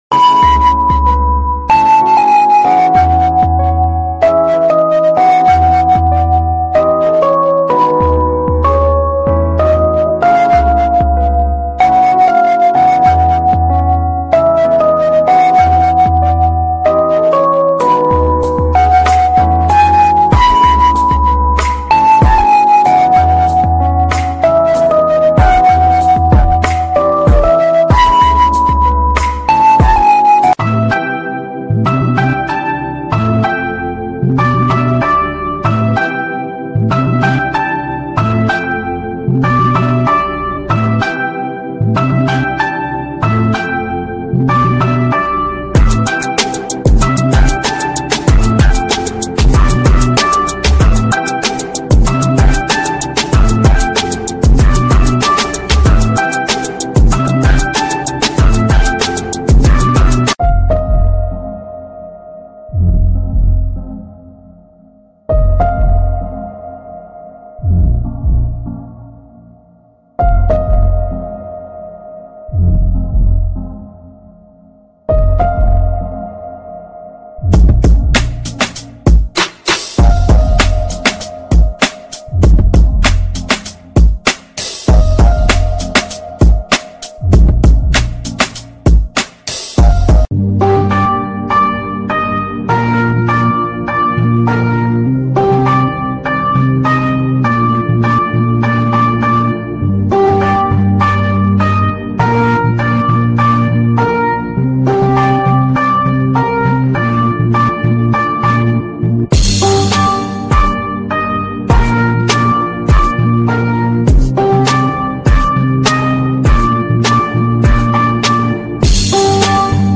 Venice Italy Walking Tour ｜ sound effects free download